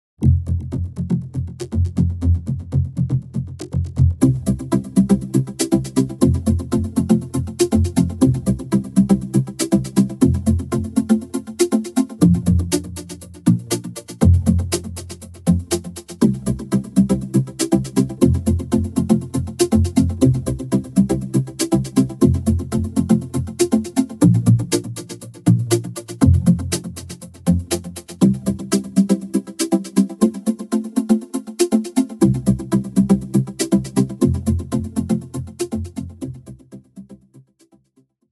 Played and recorded live, there was no additional editing, so these demos show you how Cybervox will sound when you play on a midi keyboard - live.
CV_playing_VPRex.mp3